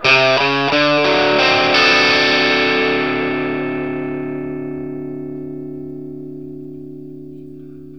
PICK1 D 7 60.wav